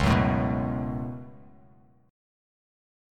C#7sus4#5 chord